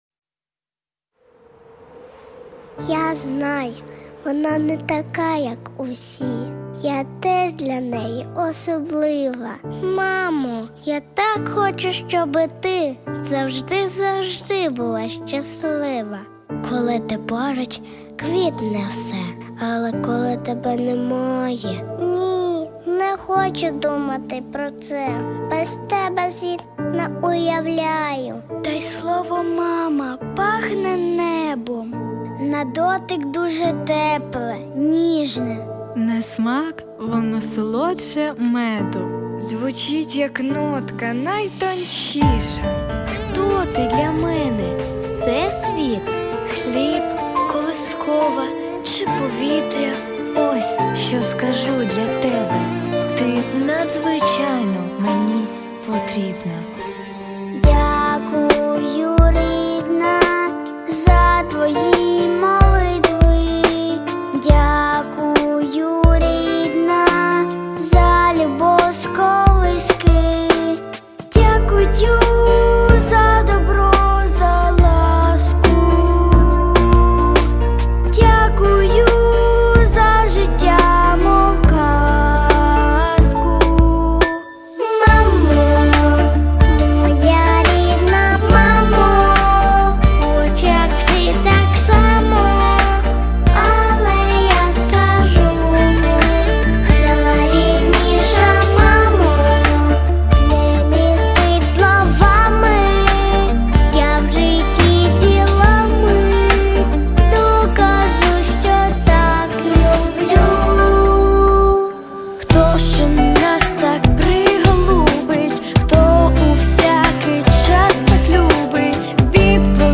• Жанр: Детские песни
христианские песни